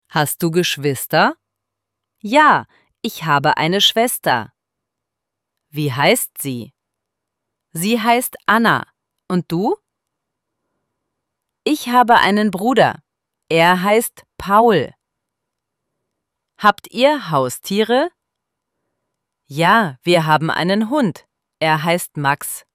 IZGOVOR – PITANJA I ODGOVORI:
ElevenLabs_Text_to_Speech_audio-45.mp3